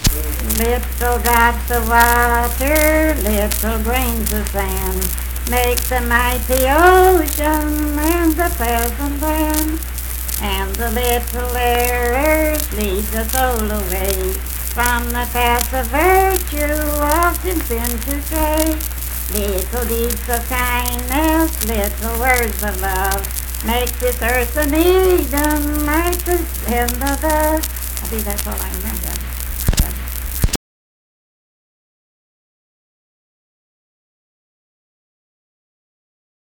Unaccompanied vocal music performance
Voice (sung)
Jackson County (W. Va.)